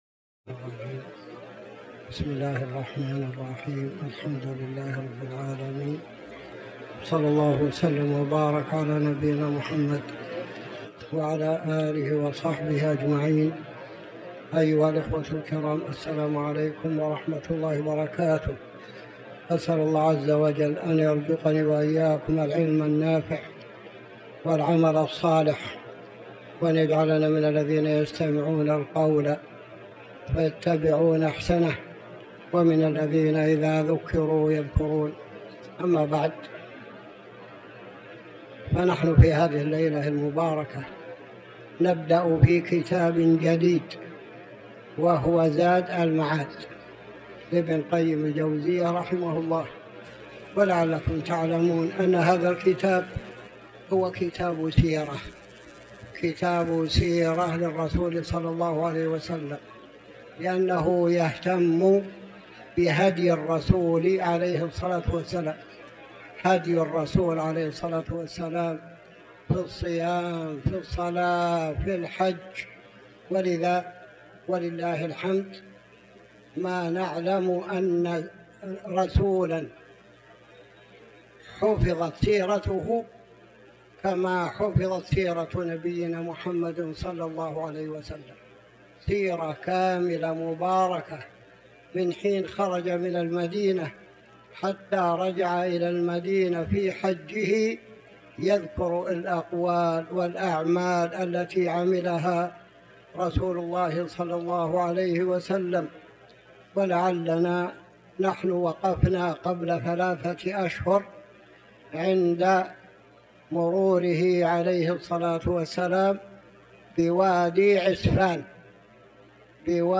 تاريخ النشر ١ جمادى الأولى ١٤٤٠ هـ المكان: المسجد الحرام الشيخ